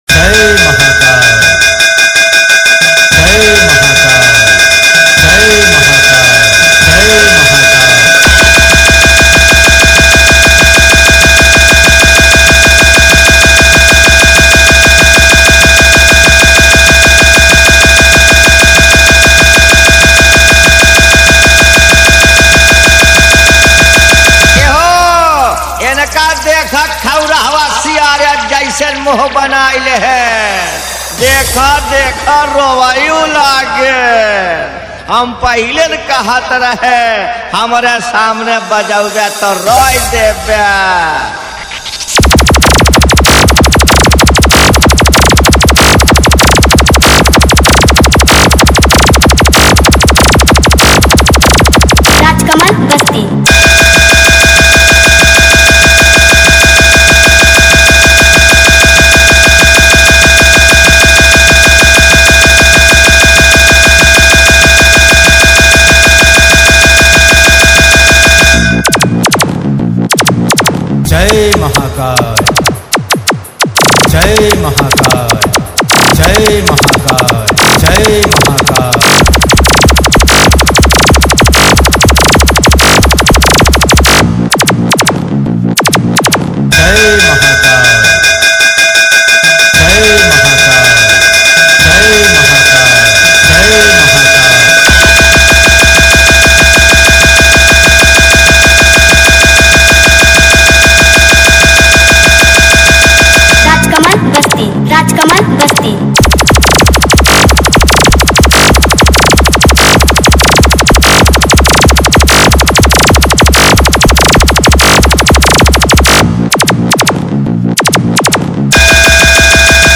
All Type Compitition Horn Music